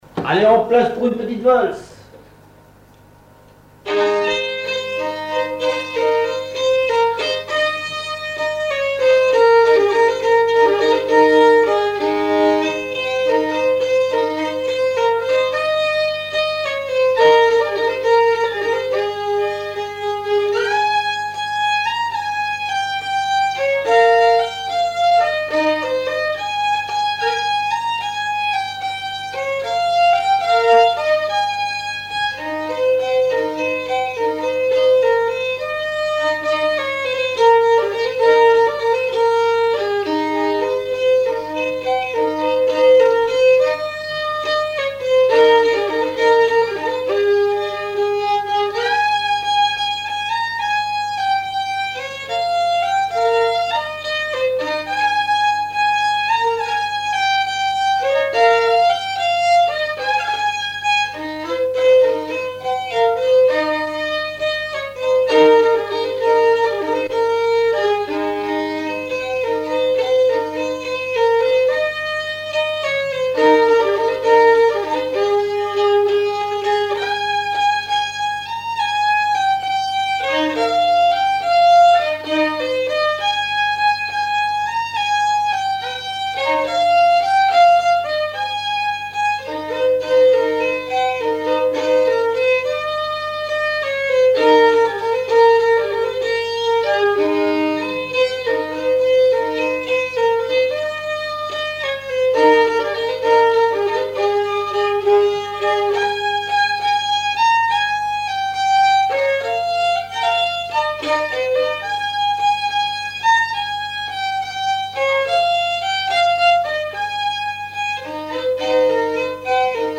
danse : valse
Auto-enregistrement
Pièce musicale inédite